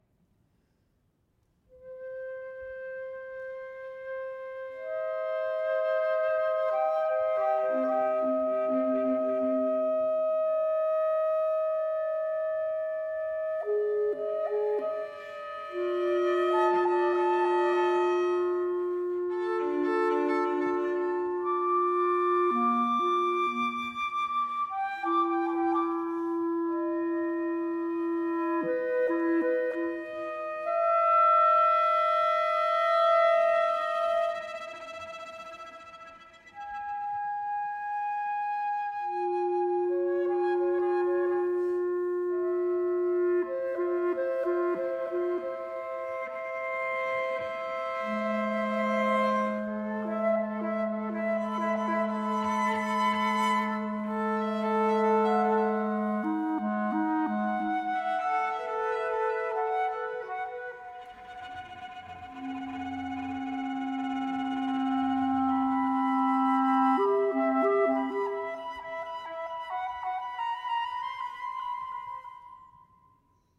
flute
cello
clarinet